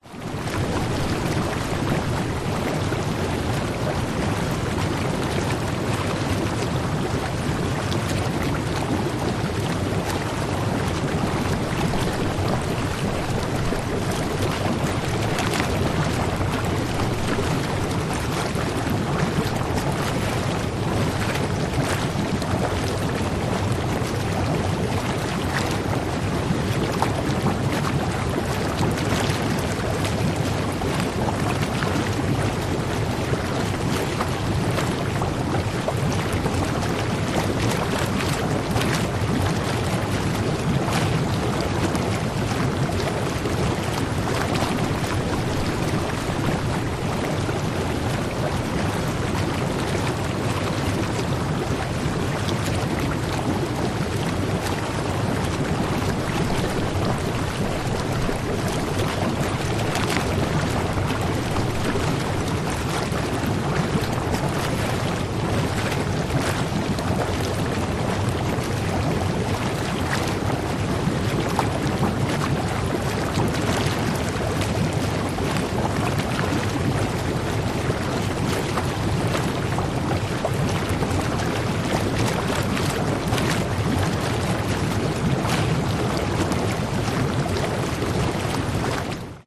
Звуки спа, атмосфера
Спа-джакузи с глубокими водными пузырями и пузырчатостью